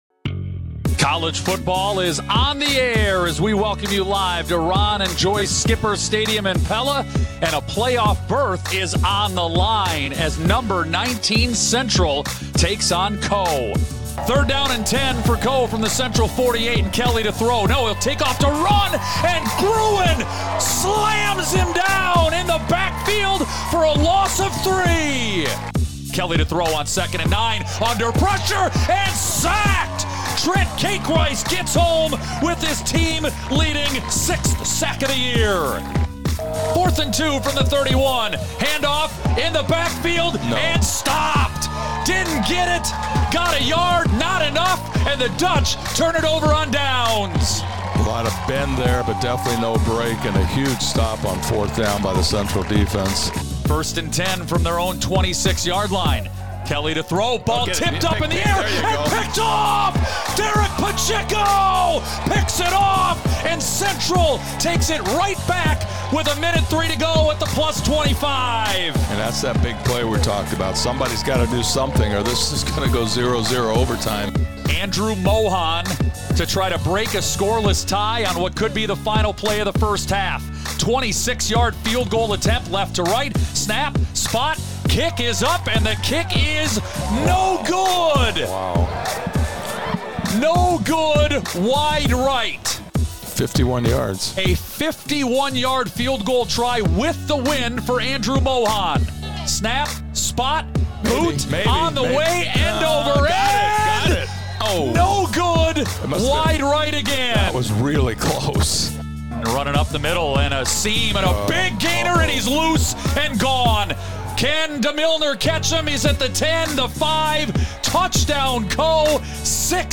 On Today’s Lely Radio Sports Page, we have highlights from our live broadcast on 92.1 KRLS and